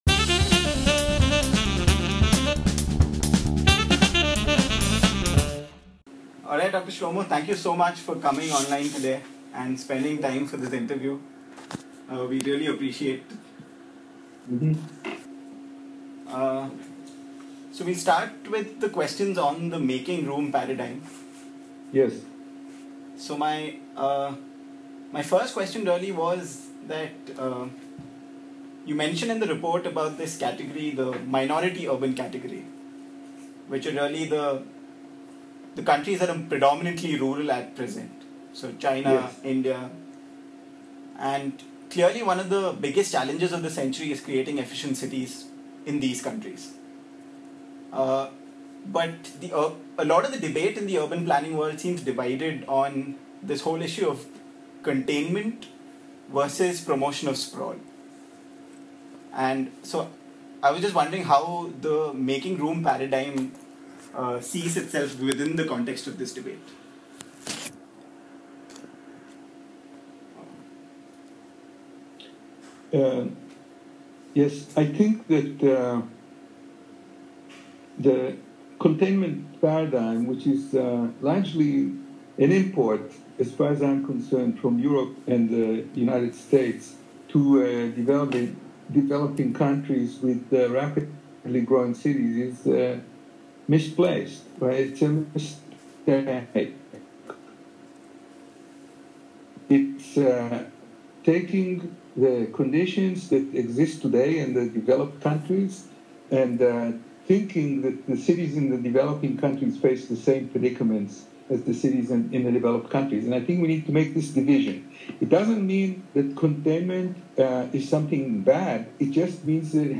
In the concluding post of this three-part interview